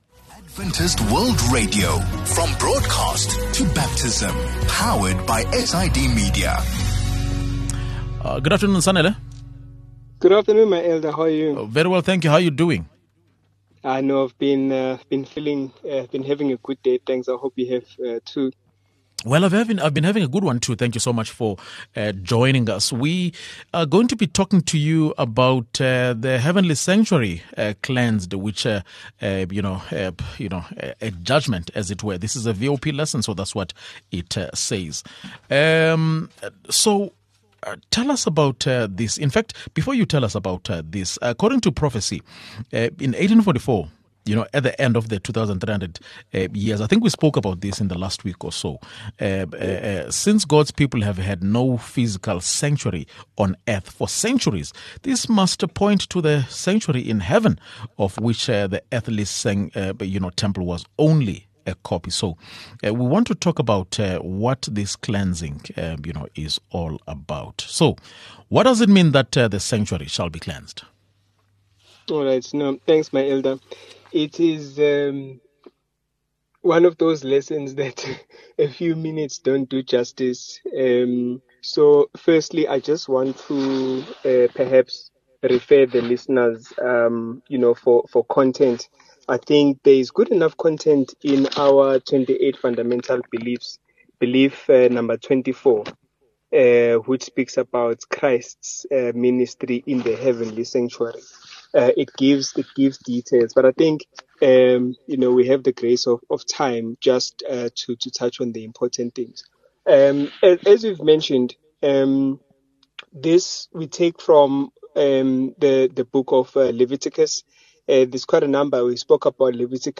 27 May VOP Lesson | The Heavenly Sanctuary Cleansed – A Judgment